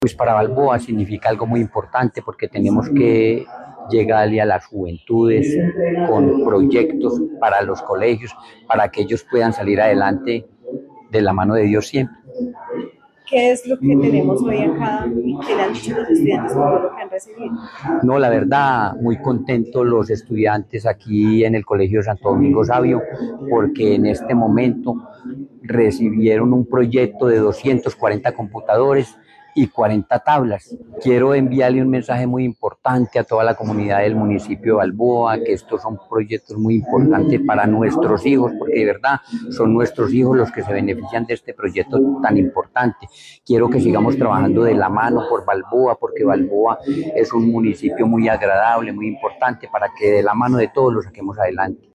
ESCUCHAR AUDIO JOSÉ MIRANDA ALCALDE DE BALBOA